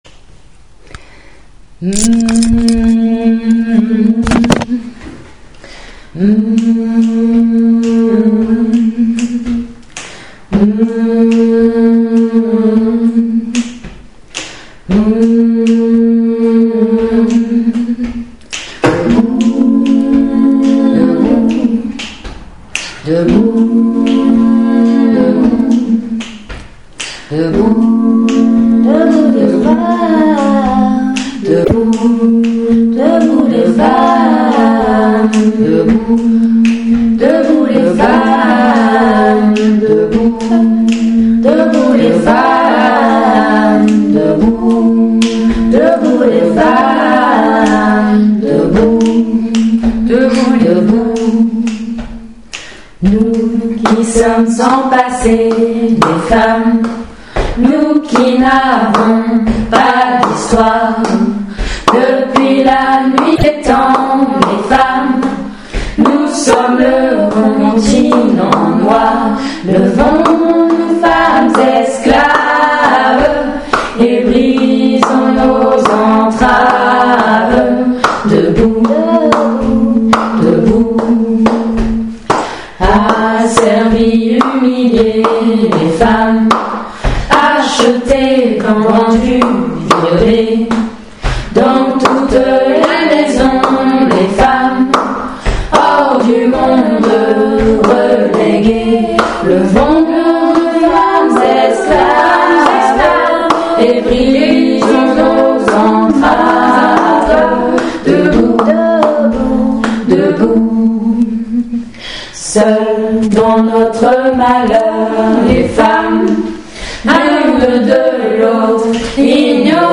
60 Un enregistrement de l’Hymne des femmes chanté par la chorale féministe 1 est disponible à l’adresse suivante :